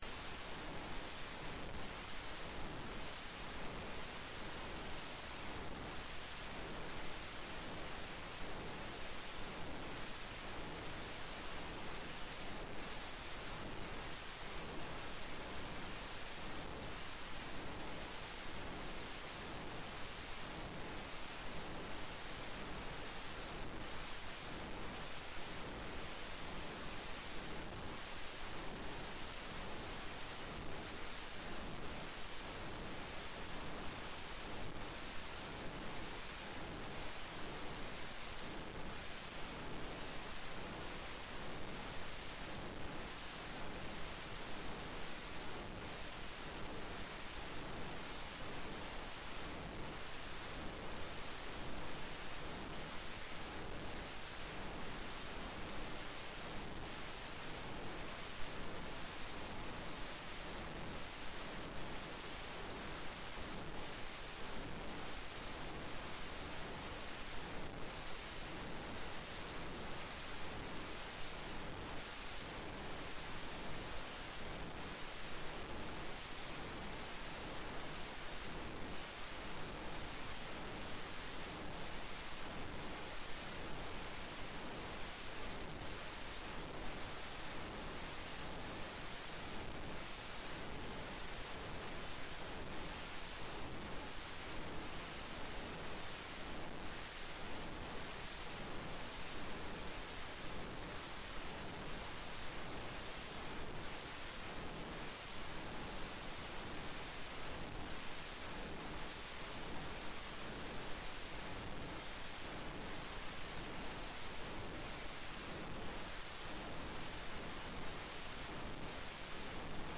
Forelesning 8.1.2020
Rom: Store Eureka, 2/3 Eureka